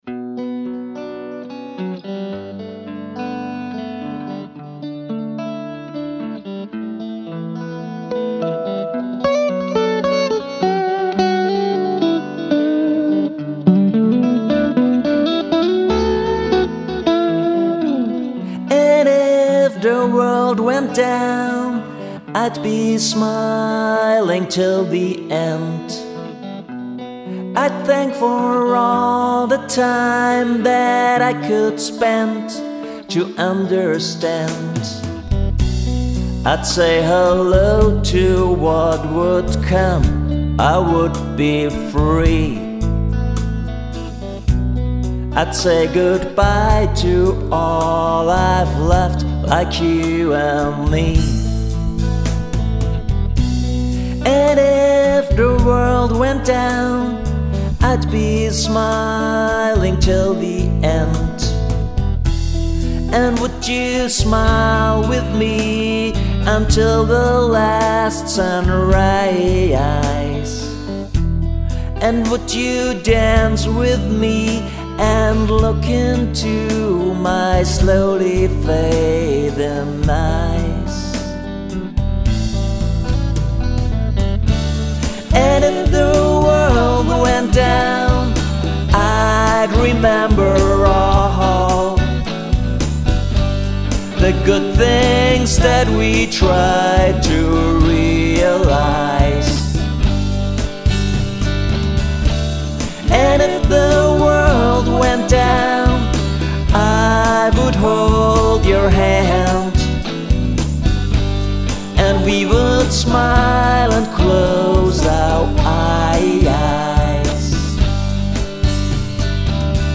vocals, electric guitars. bass, drum programming